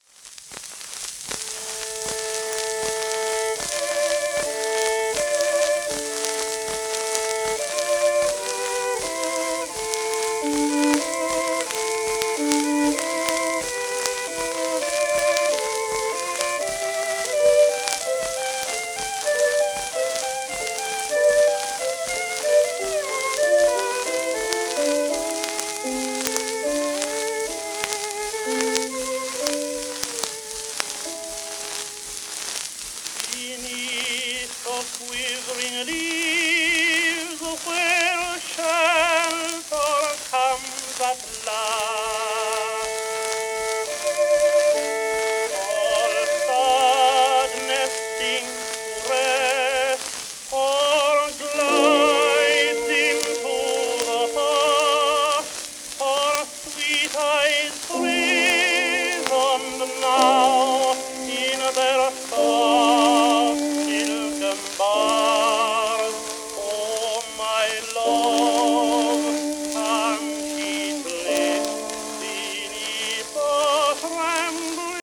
1914年録音
旧 旧吹込みの略、電気録音以前の機械式録音盤（ラッパ吹込み）